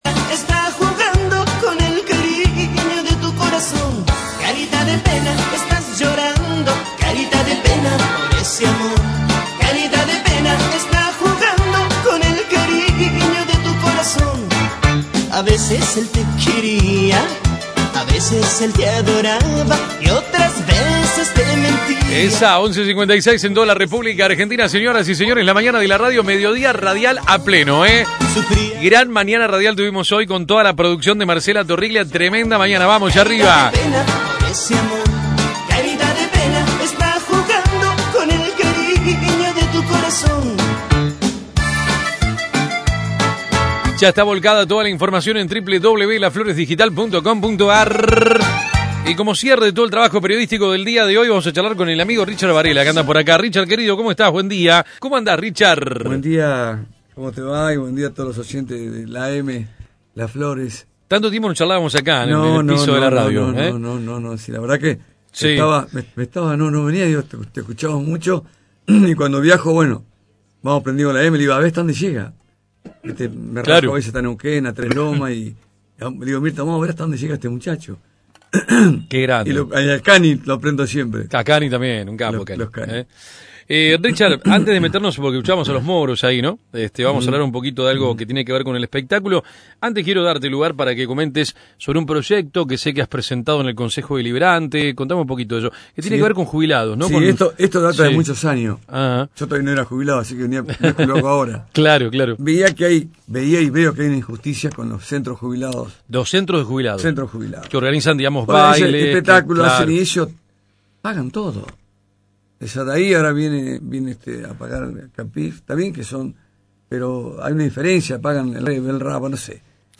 visitó los estudios de AM 1210